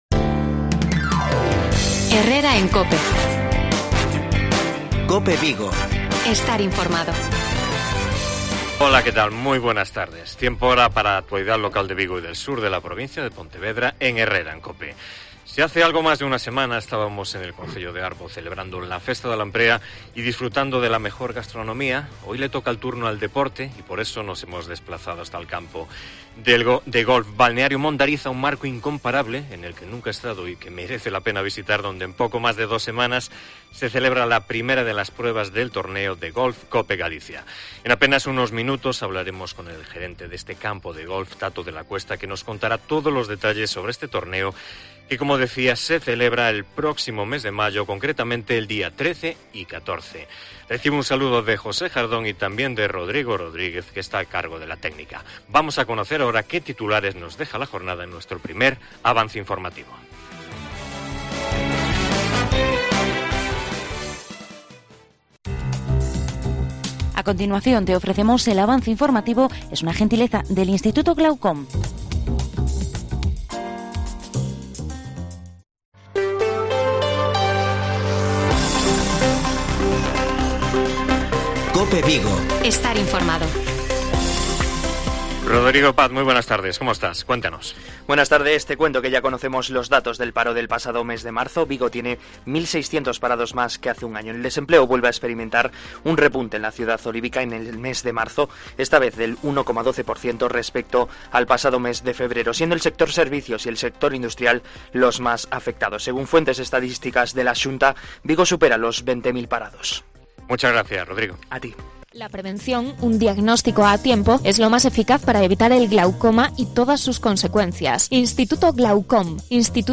AUDIO: ESPECIAL PRESENTACION TORNEO DE GOLF COPE GALICIA DESDE GOLF BALNEARIO DE MONDARIZ